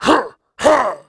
sound / monster2 / fire_knight / attack_1.wav
attack_1.wav